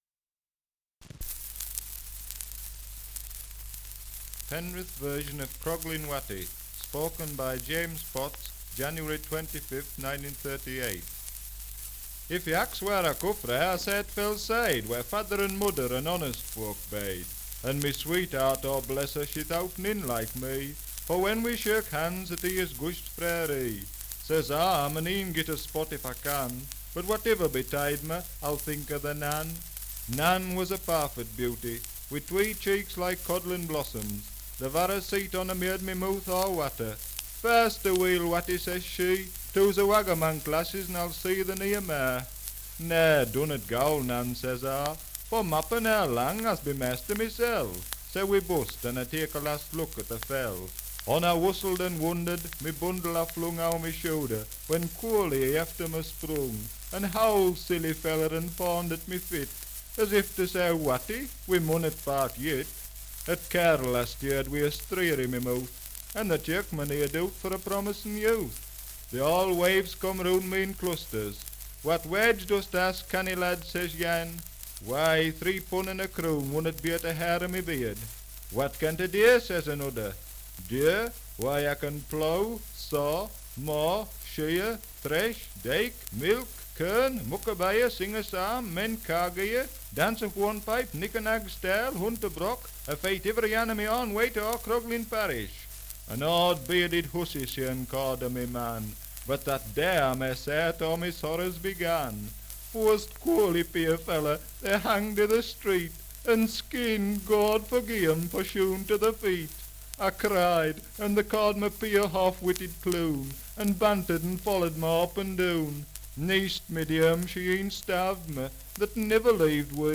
Dialect recording in Penrith, Cumberland
78 r.p.m., cellulose nitrate on aluminium
English Language - Dialects
Oral Traditions